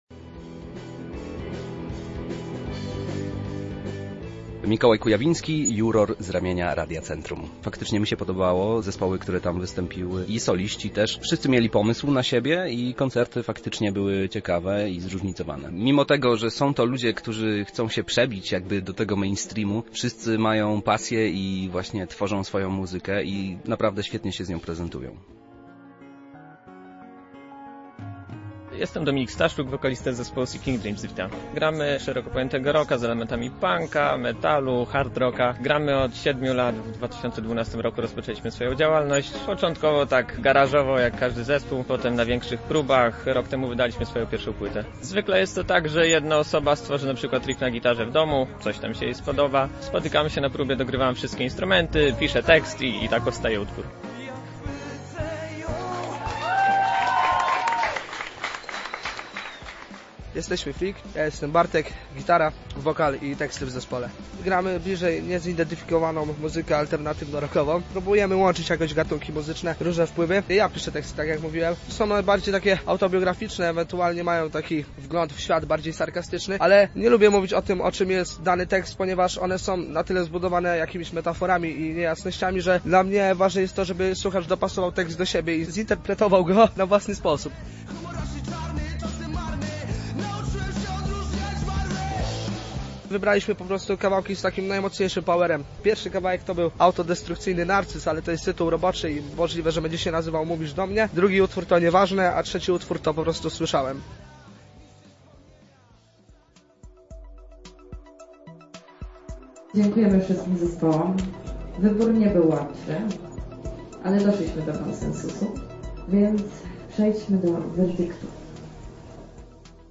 W tym roku poznaliśmy 10 debiutujących zespołów. Artyści reprezentowali różne style i gatunki muzyczne – a na miejscu była nasza reporterka: